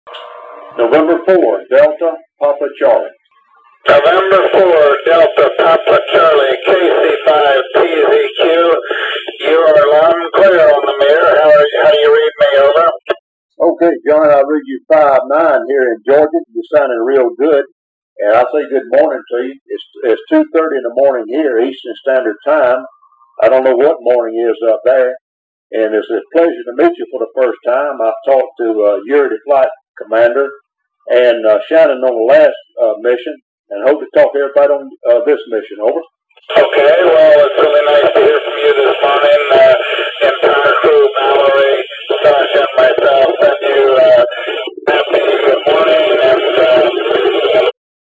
These recordings were converted to files with a nice program that filters much of the noise and allows editing to cut out undesirable areas of noise and silence.